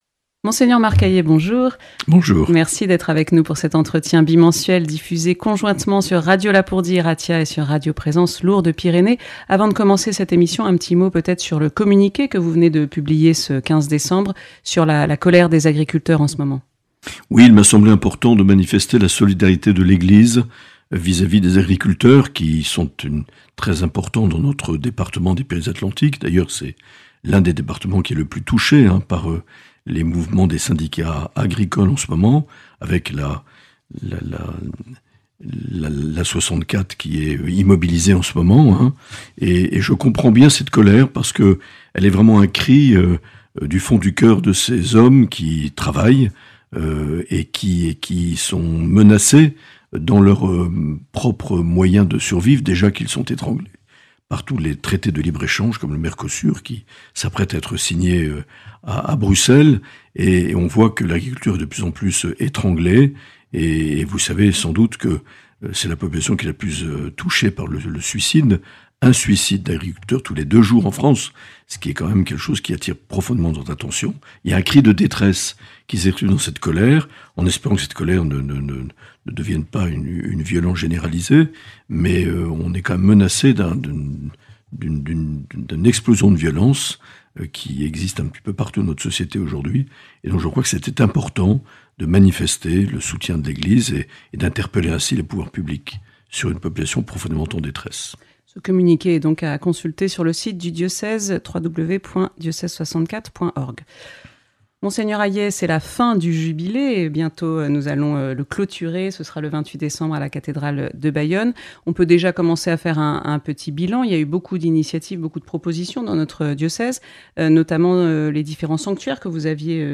Entretien avec l’Evêque
Entretien réalisé le 18 décembre 2025.